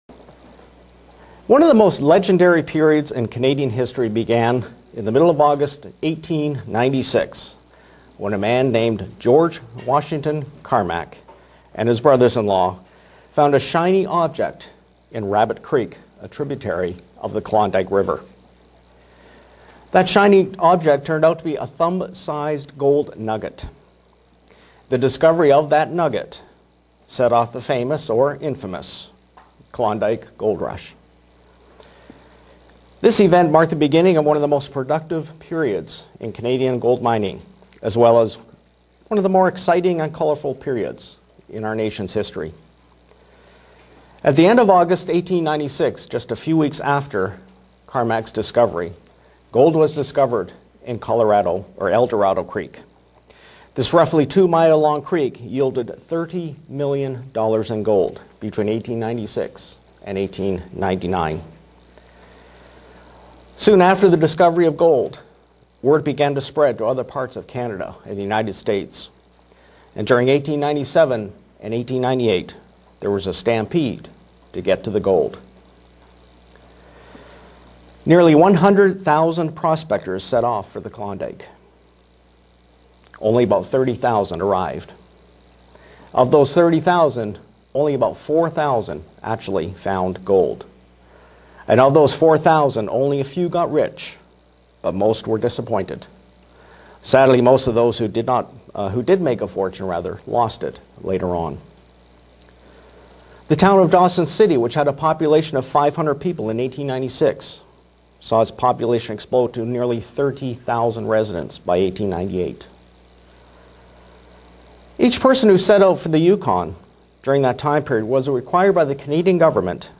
Print To compare gold's properties to how God could view us. sermon Studying the bible?
Given in Buffalo, NY Elmira, NY